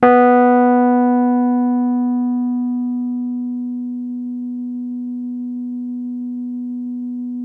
RHODES CL0AL.wav